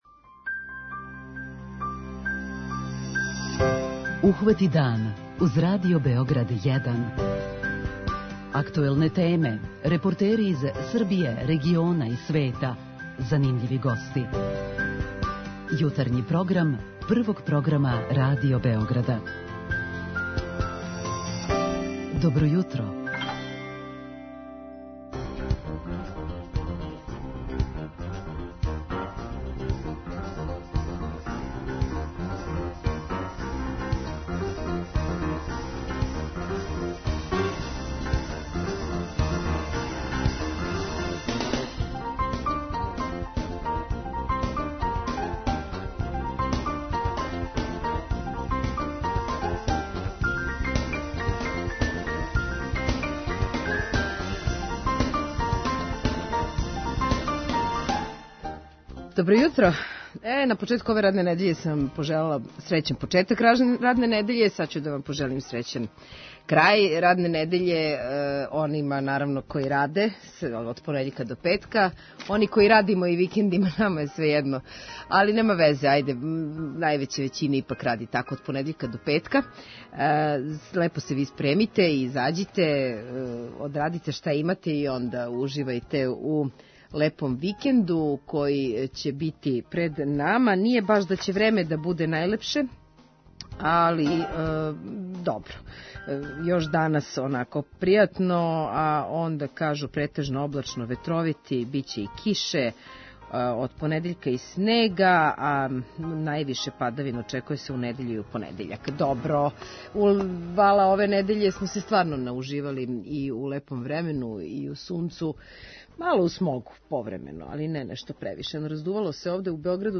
преузми : 37.77 MB Ухвати дан Autor: Група аутора Јутарњи програм Радио Београда 1!